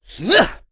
Kasel-Vox_Attack2.wav